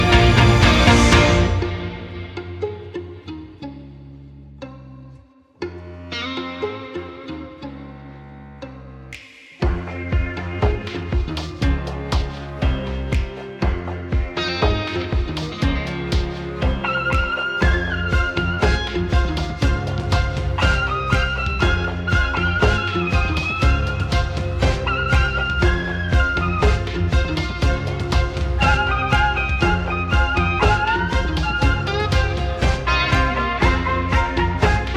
# Classical Crossover